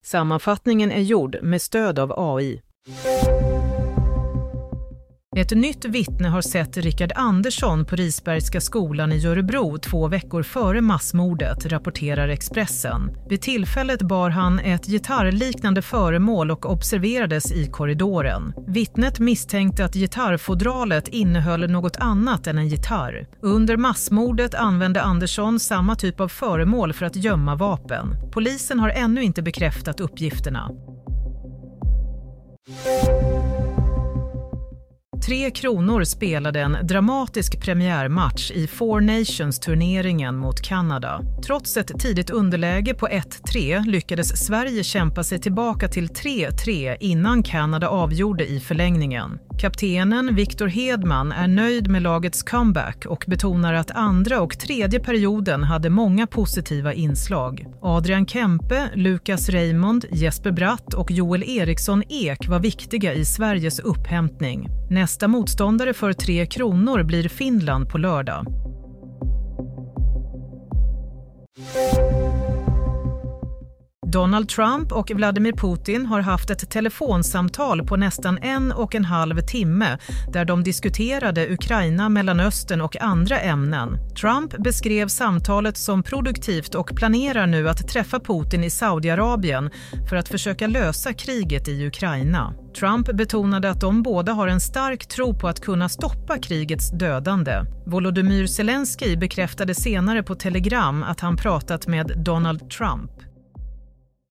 Nyhetssammanfattning - 13 februari 07.30